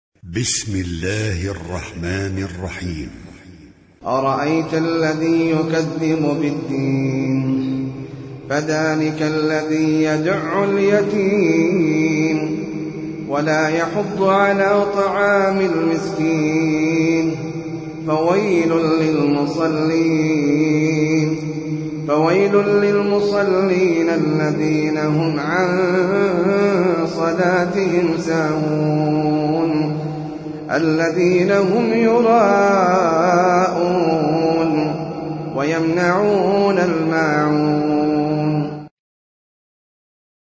Sûrat Al-Ma'un (Small Kindnesses) - Al-Mus'haf Al-Murattal